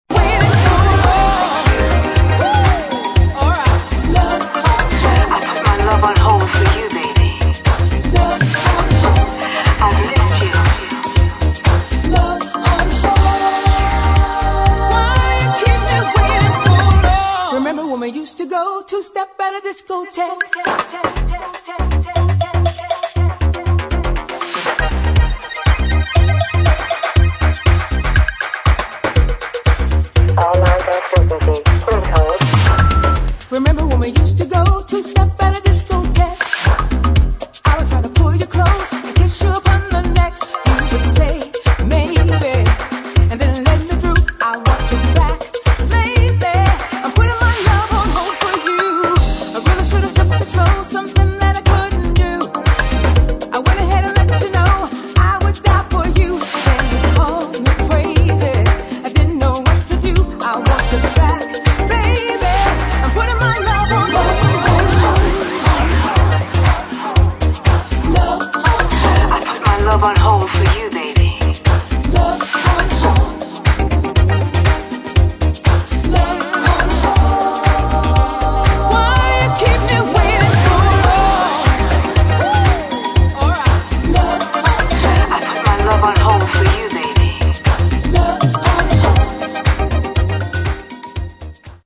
[ DISCO ]
DJ Friendly Re-Touch